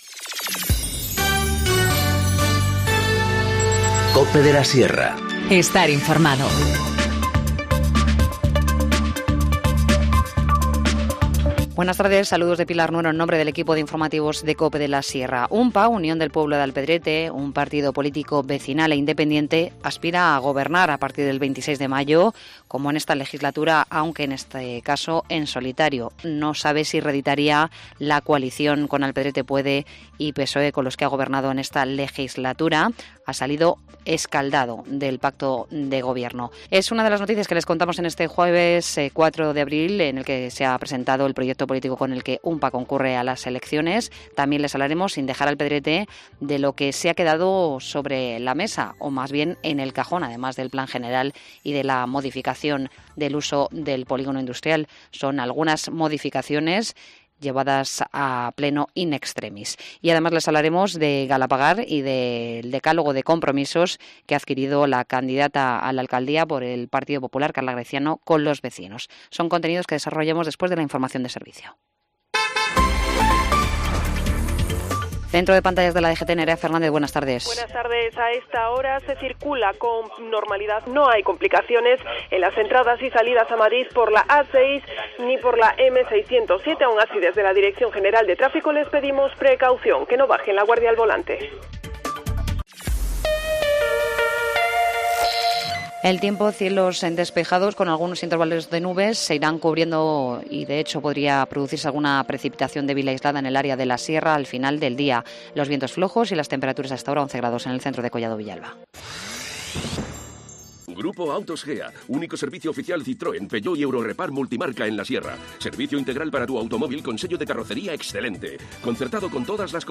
Informativo Mediodía 4 abril 14:20h